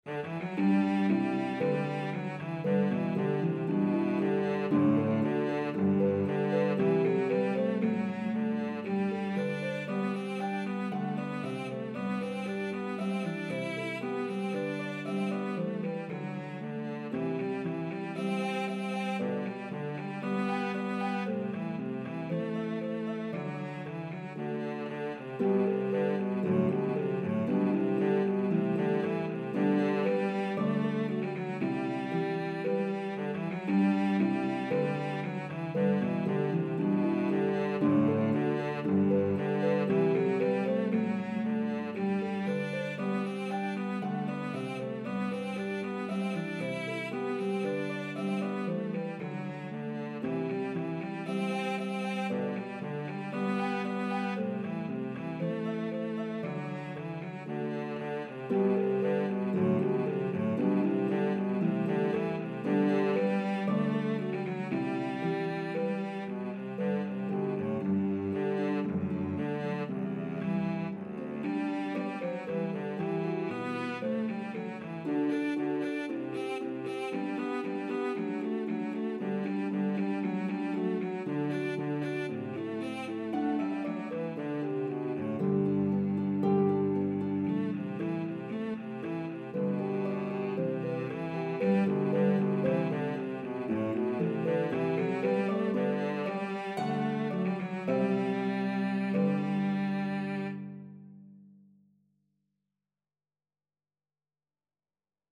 the popular Italian style of the day